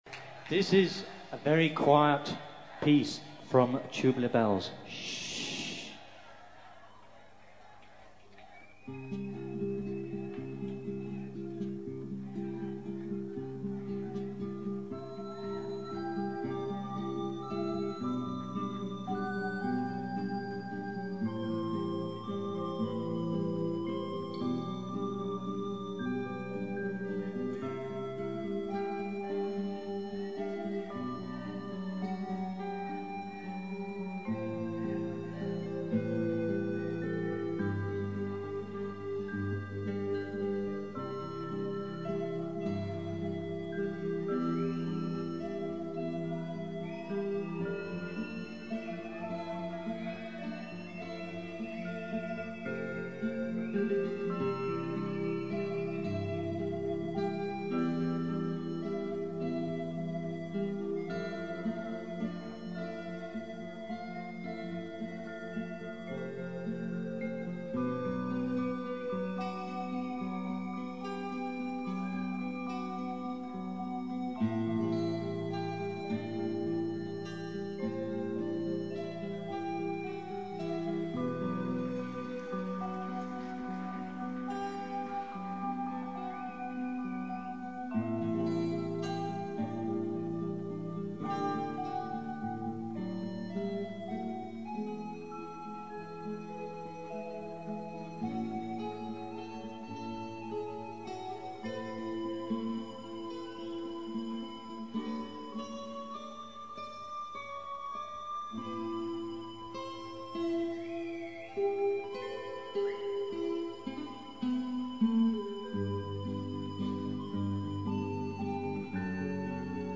en mono
extracto en DIRECTO en Hannover (Alemania)